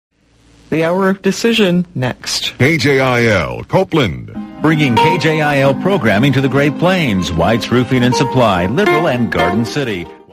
KJIL Top of the Hour Audio: